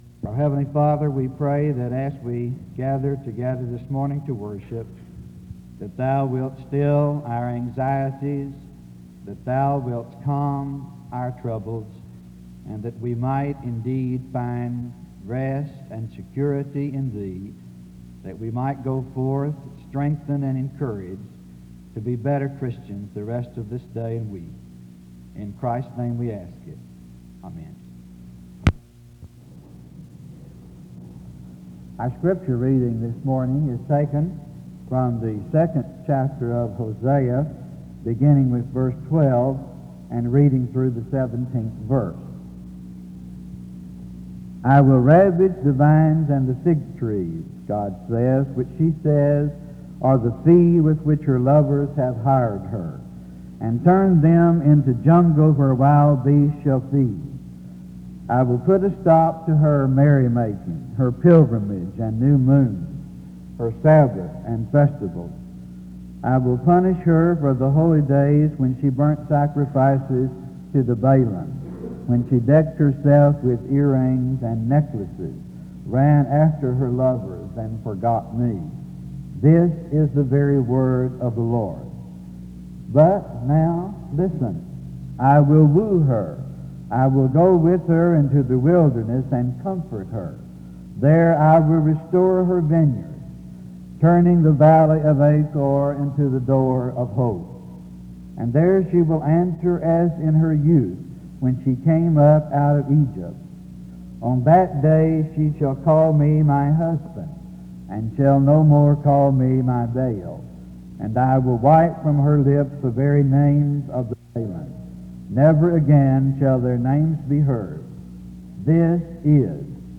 The service opens with prayer from 0:00-0:28. Scripture reading takes place from 0:31-2:07.